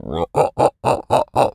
lizard_taunt_emote_01.wav